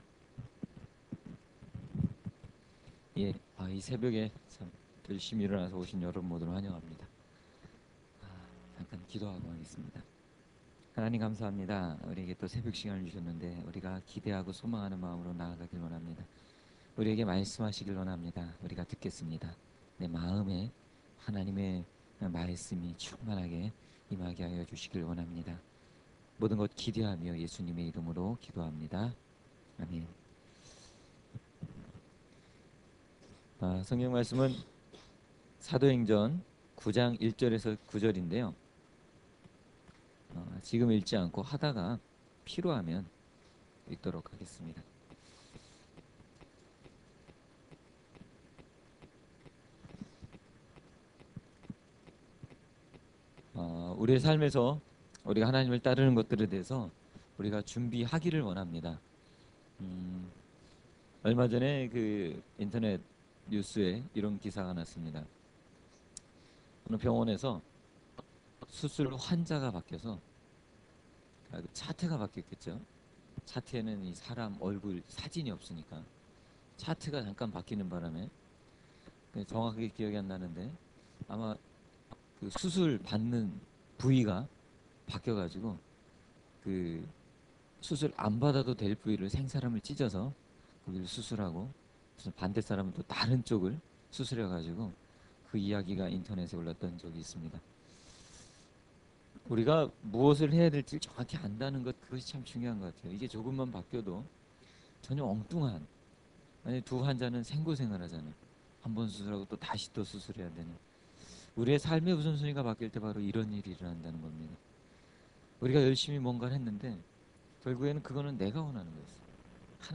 청년부 동계 수련회 2월 10일 새벽 집회
설교